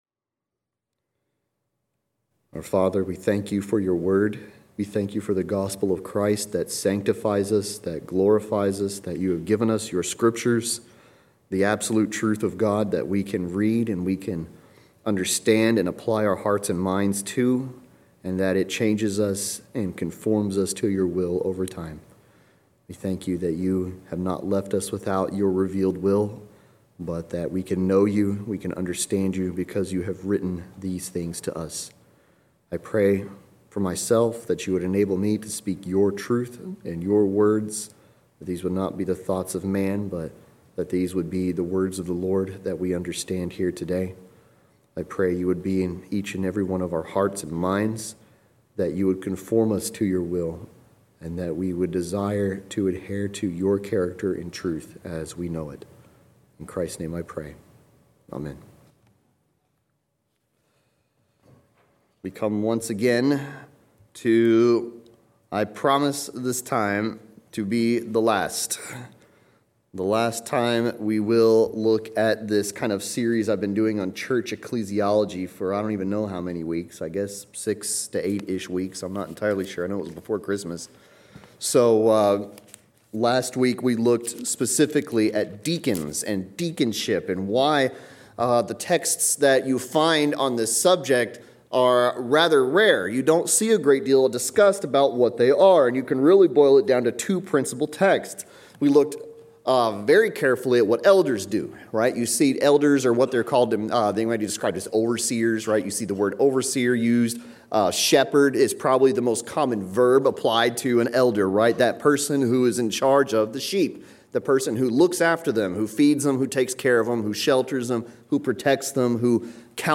Acts 6:1-7 Service Type: Sunday Sermon We finish up looking at deacons and elders in this message.